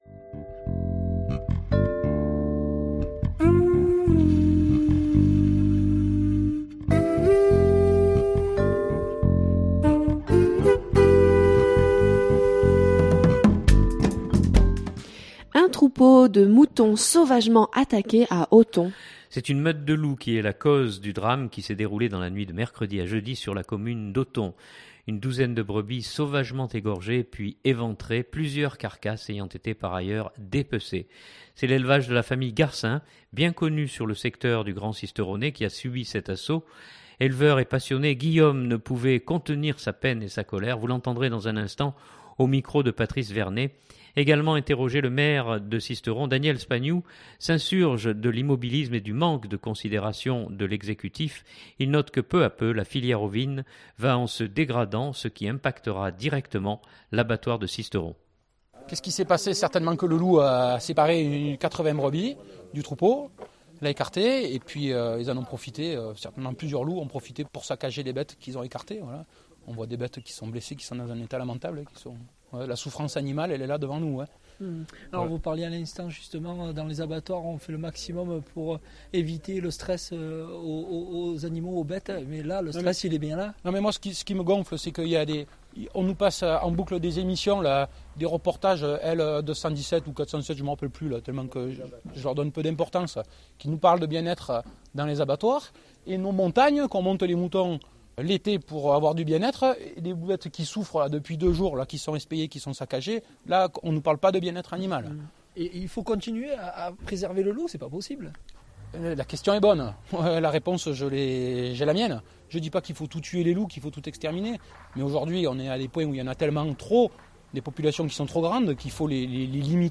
Également interrogé, le maire Daniel Spagnou s’insurge de l’immobilisme et du manque de considération de l’exécutif.